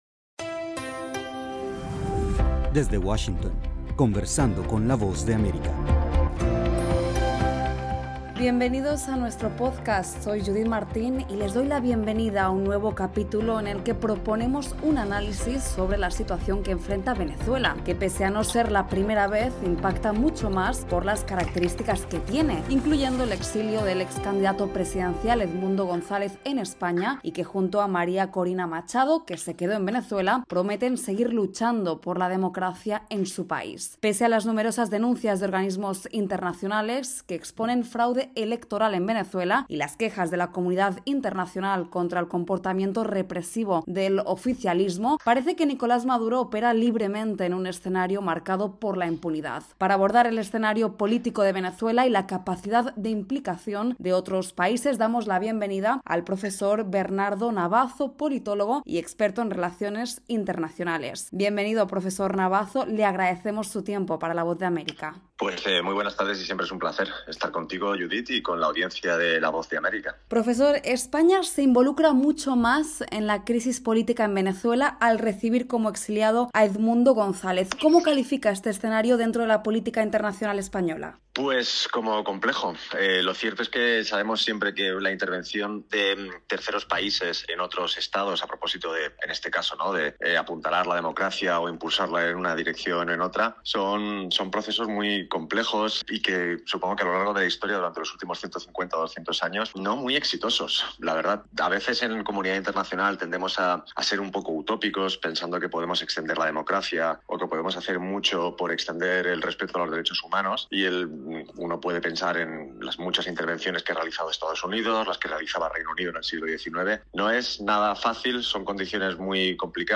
La crisis política en Venezuela inicia un nuevo capítulo tras el exilio del líder opositor Edmundo González. Para analizar las capacidades de influencia de terceros países sobre Venezuela conversamos con el experto en Relaciones Internacionales y analista político